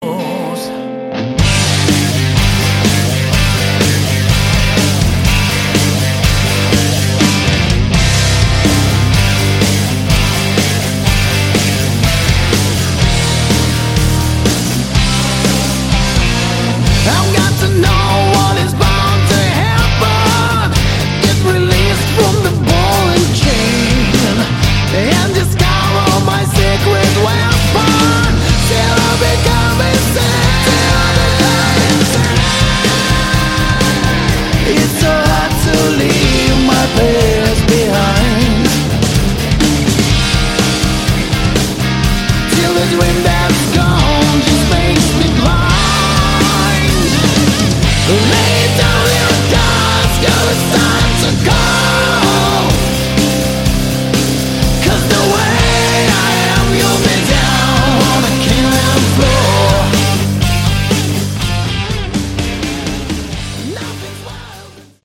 Category: Hard Rock
guitar, backing vocals
lead vocals
bass, backing vocals
drums